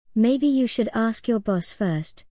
イギリス発音では、アメリカ発音で/æ/と発音する単語のいくつかを/ɑː/と発音します。
▶イギリス英語